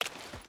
Water Walk 3.wav